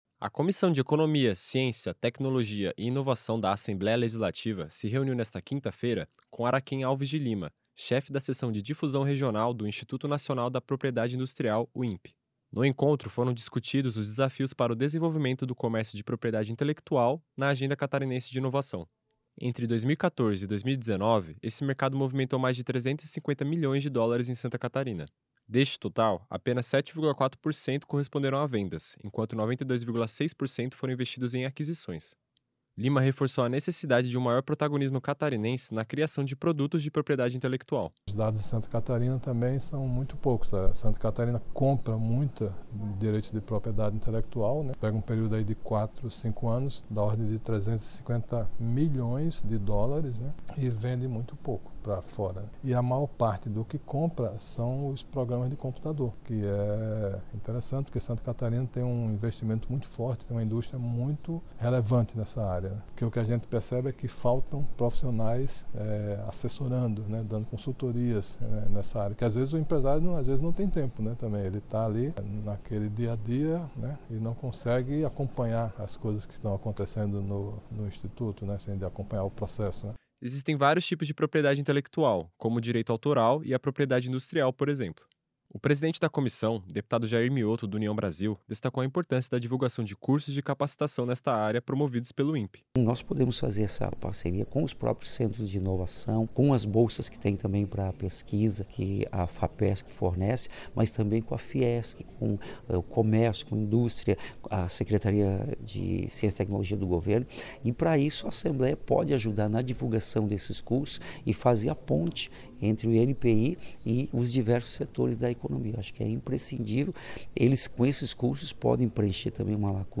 Entrevistas com:
- deputado Jair Miotto (União), presidente da comissão.